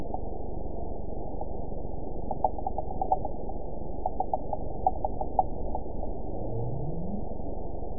event 912155 date 03/19/22 time 12:33:47 GMT (3 years, 2 months ago) score 9.05 location TSS-AB05 detected by nrw target species NRW annotations +NRW Spectrogram: Frequency (kHz) vs. Time (s) audio not available .wav